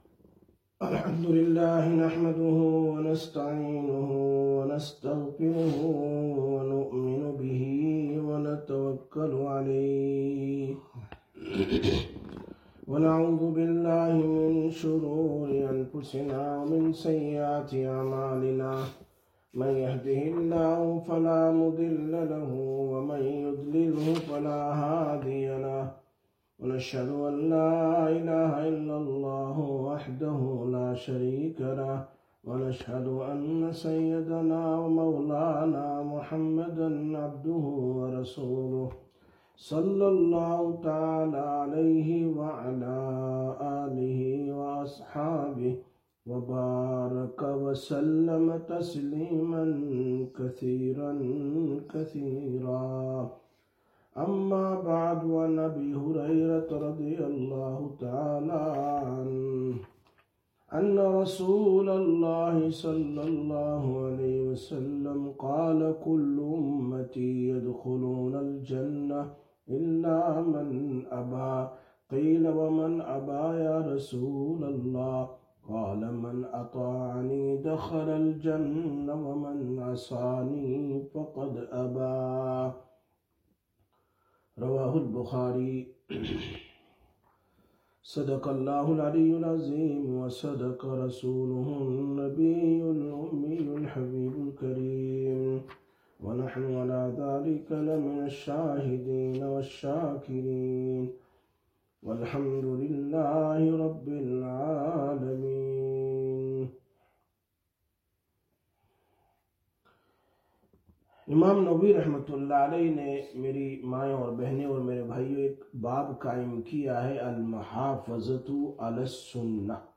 27/08/2025 Sisters Bayan, Masjid Quba